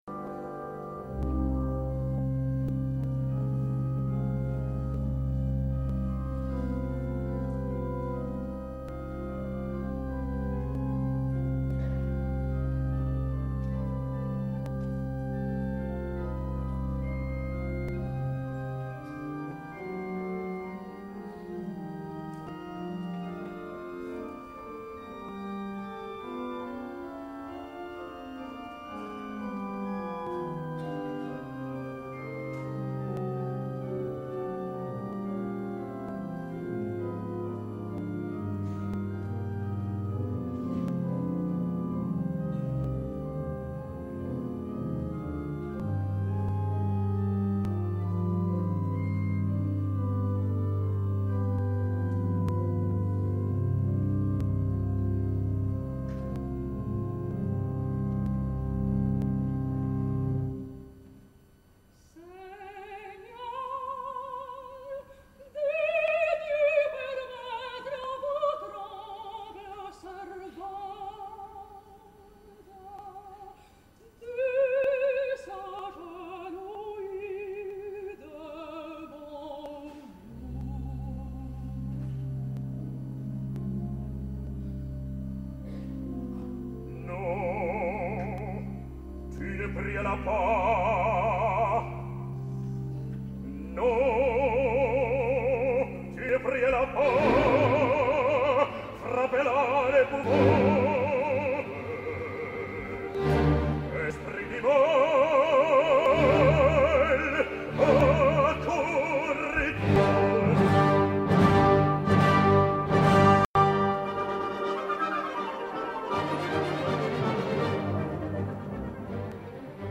Tots els fragments son del dia de l’estrena, 11 d’octubre de 2008.
La soprano romanesa, posseïdora d’una veu lírica, ample i carnosa, ideal pel rol de Marguerite, evita la millor ària de l’obra, la dramàtica “Il ne revient pas!”, abans de la cabdal escena de l’església amb Méphistophélès.
Ara escoltem a Angela Gheorghiu i Kwangchul Youn a l’escena de l’església “Seigneur, daigmez permettre…Souviens-toi du passé”
escena-acte-iv.mp3